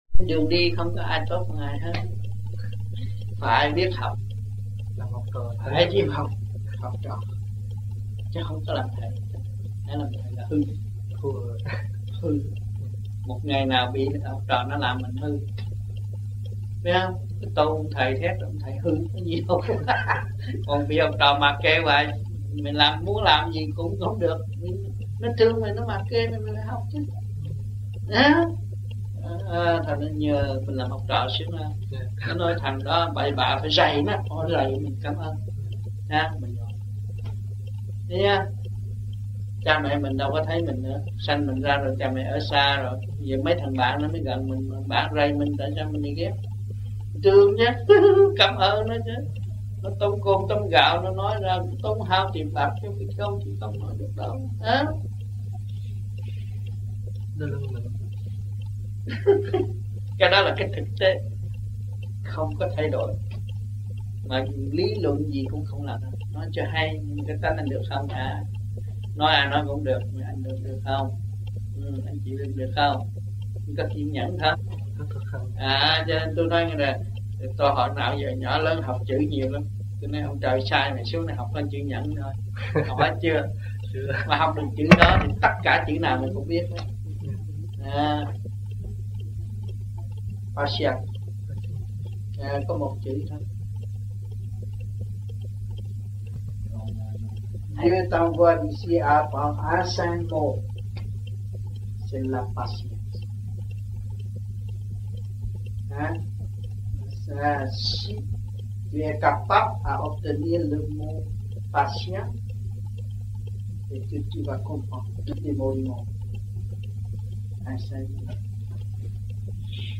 1982-12-16 - Toulouse - Thuyết Pháp 1